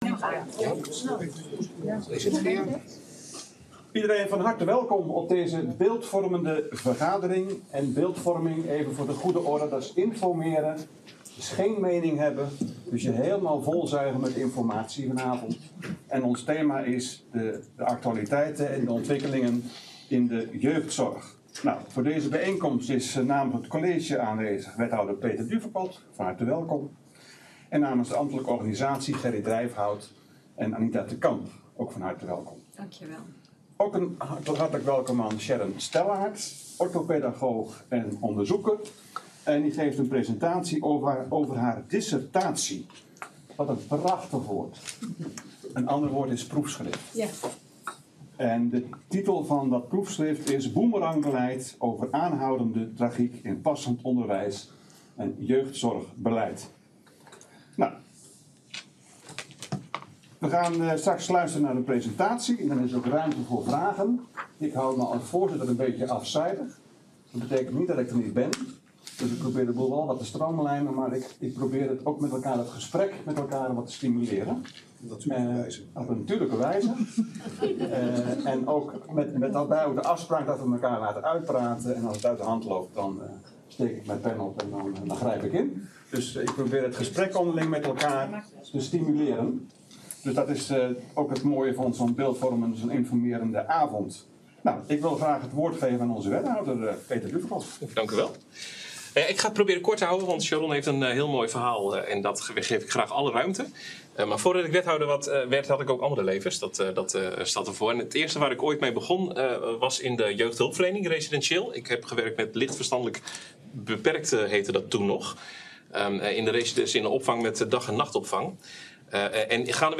Beeldvormende vergadering 09 februari 2023 20:45:00, Gemeente Dronten
Locatie: Raadzaal